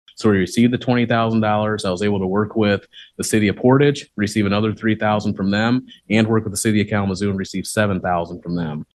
County Administrator Kevin Catlin says sometimes it takes more than just a good idea, it takes funding, so he asked the Kalamazoo Foundation to help.